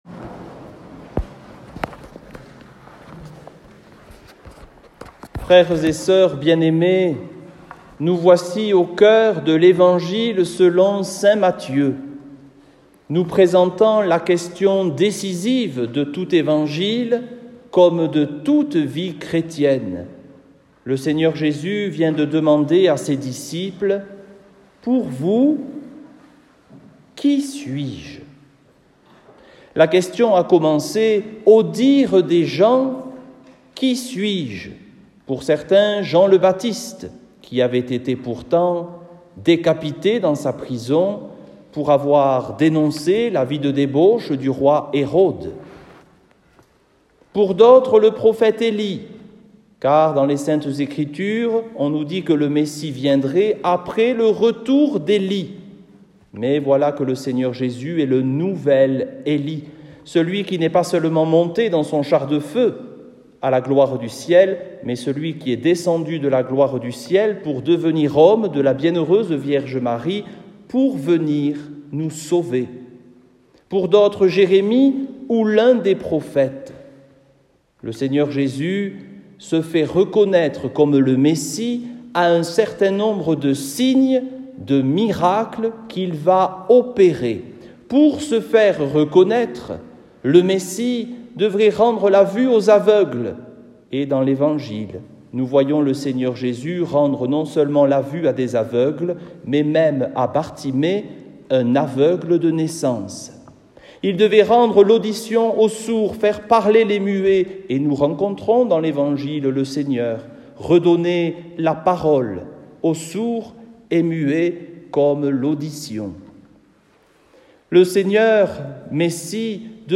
Homélie au XXIe Dimanche du Temps Ordinaire - Vox in deserto
Ecouter l’homélie donnée en l’église de Gavarnie :
homelie-au-xxie-dimanche-du-temps-ordinaire.mp3